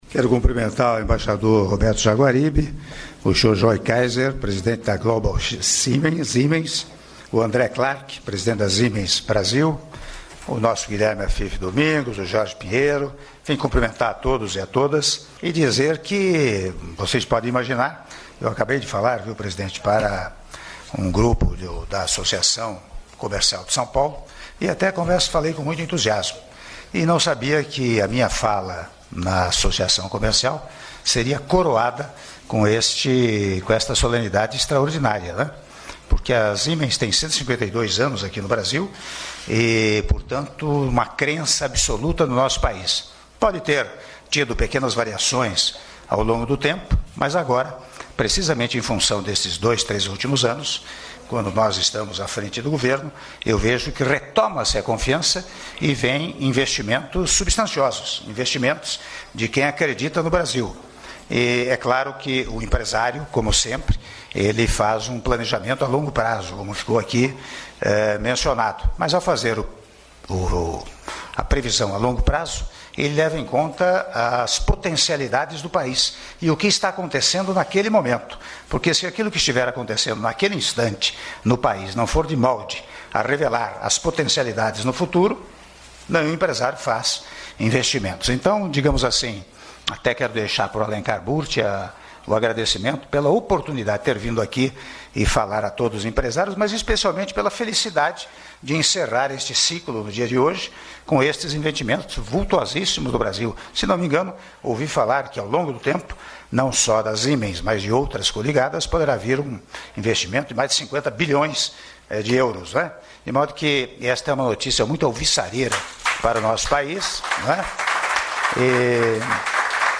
Áudio do discurso do Presidente da República, Michel Temer, durante Cerimônia de Assinatura de Ato entre a APEX e a Empresa Siemens Global - (04min16s) - São Paulo/SP